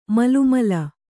♪ malumala